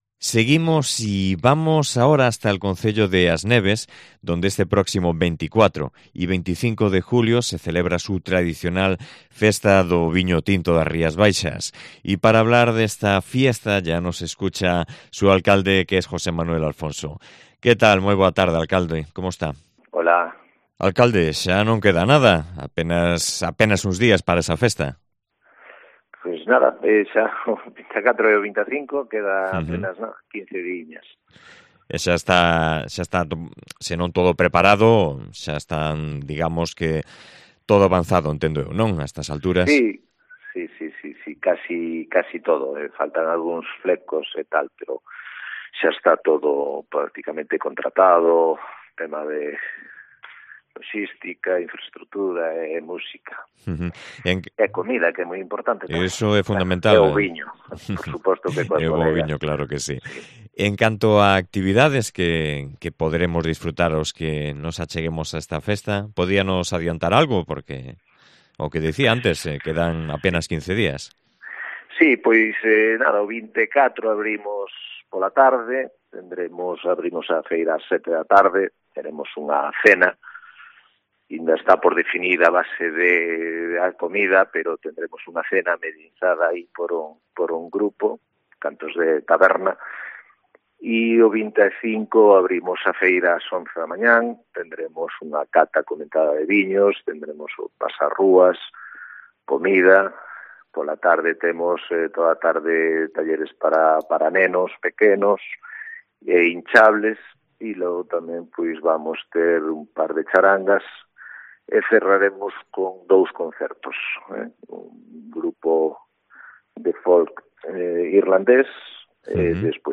AUDIO: Con su alcalde, José Manuel Alfonso, hablamos de esta tradicional fiesta que se celebra el 24 y 25 de julio